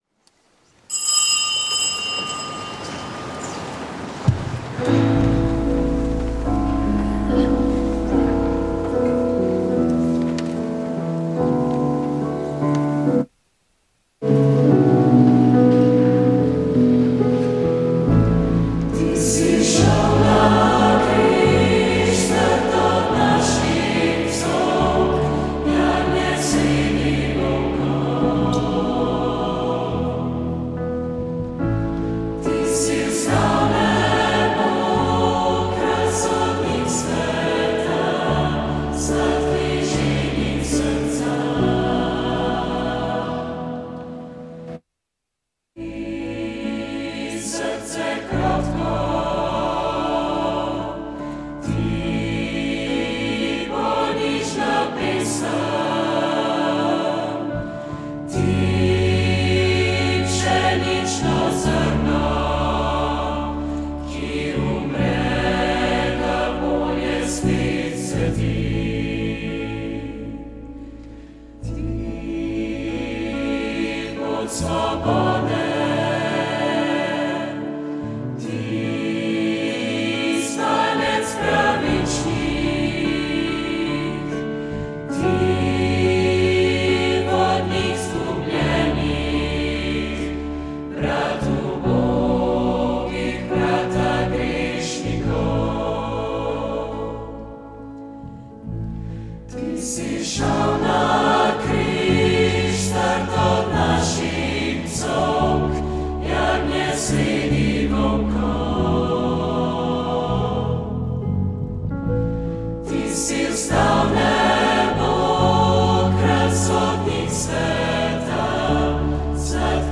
Sveta maša
Sv. maša iz cerkve Marijinega oznanjenja na Tromostovju v Ljubljani 11. 3.